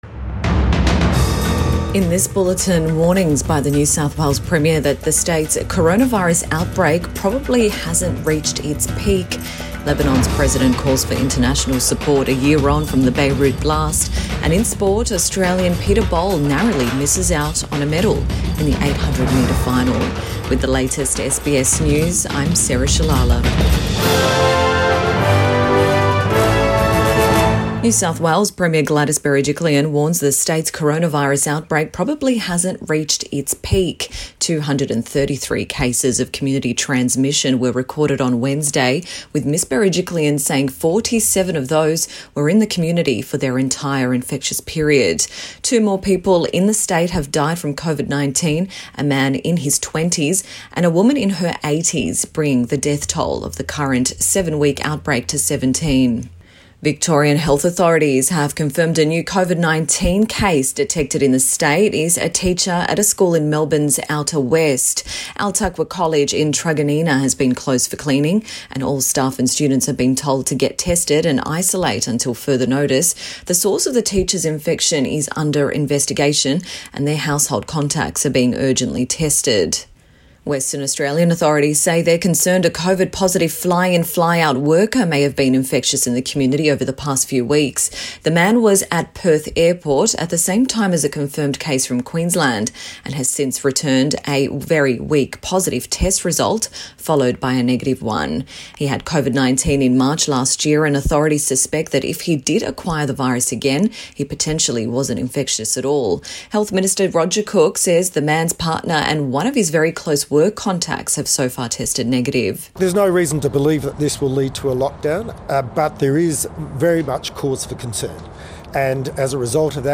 AM Bulletin 5 August 2021